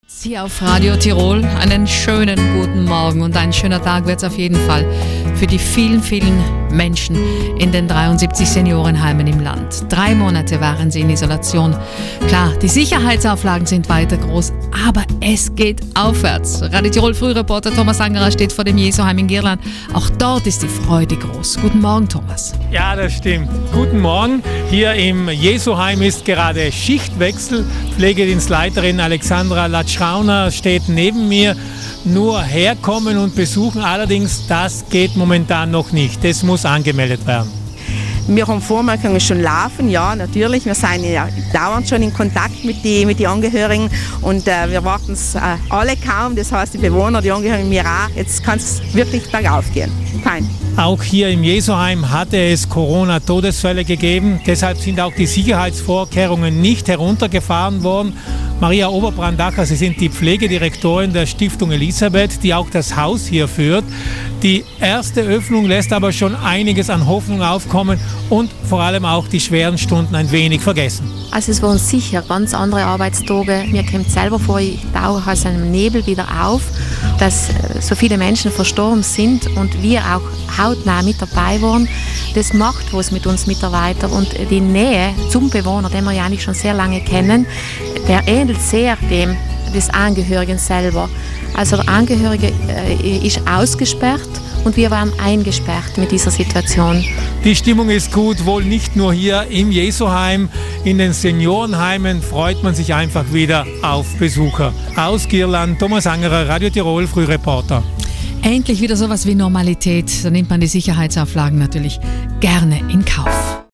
im Jesuheim in Girlan vorbeigeschaut.